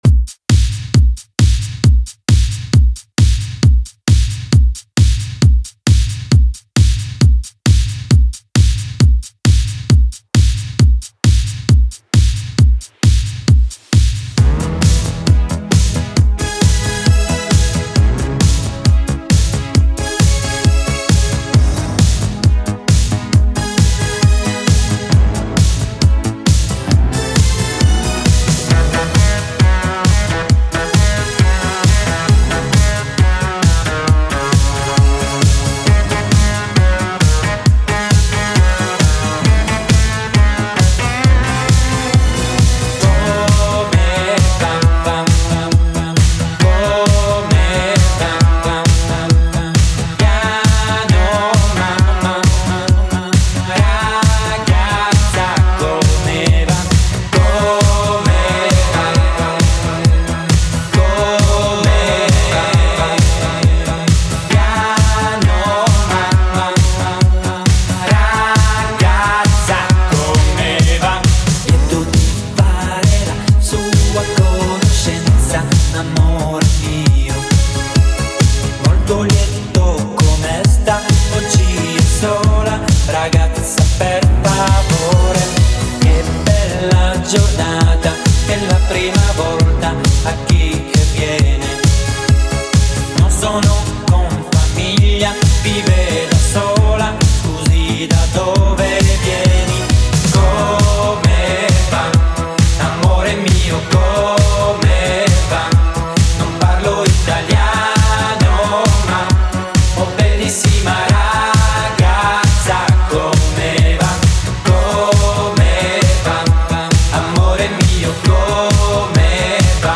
Genre: 60's Version: Clean BPM: 114